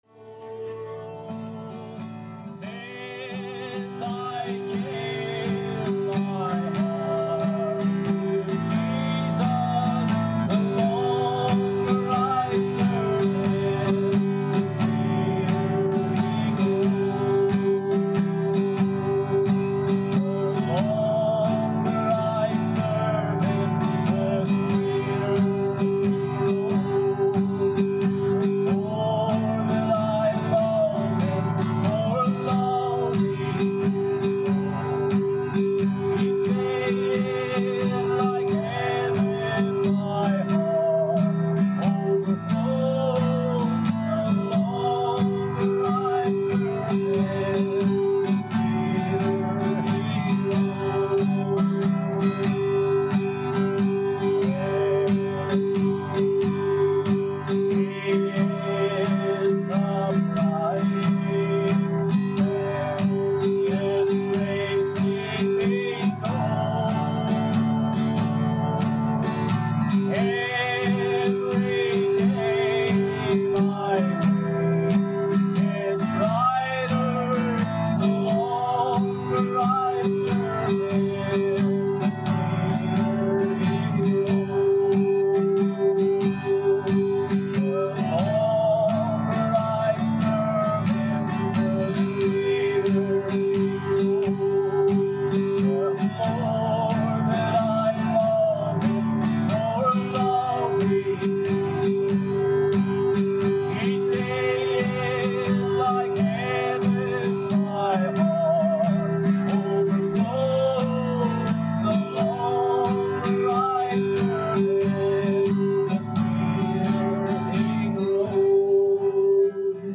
Omega Ministry - Audio Sermons